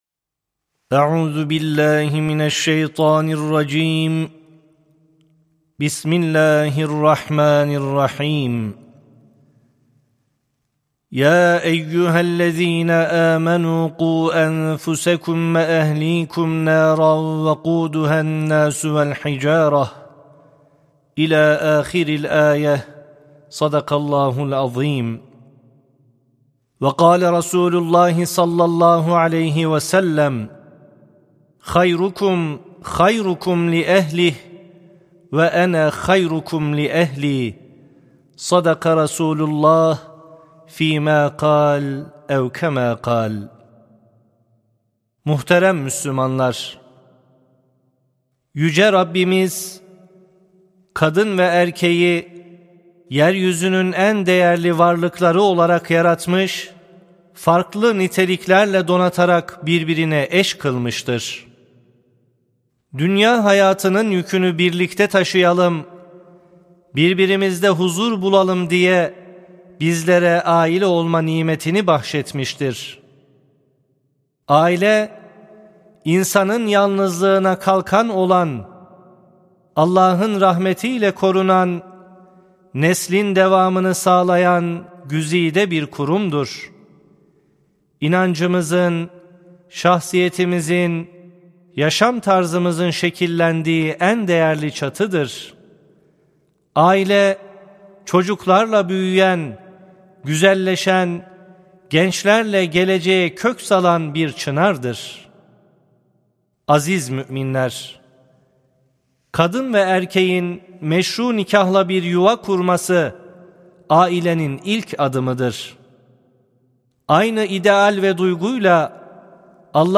17.05.2024 Cuma Hutbesi: Ailemiz: Huzur ve Güven Kaynağımız (Sesli Hutbe, Türkçe, İngilizce, Rusça, İspanyolca, Almanca, Fransızca, İtalyanca, Arapça)
Sesli Hutbe (Ailemiz; Huzur ve Güven Kaynağımız)